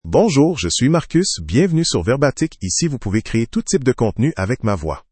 Marcus — Male French (Canada) AI Voice | TTS, Voice Cloning & Video | Verbatik AI
MaleFrench (Canada)
Marcus is a male AI voice for French (Canada).
Voice sample
Marcus delivers clear pronunciation with authentic Canada French intonation, making your content sound professionally produced.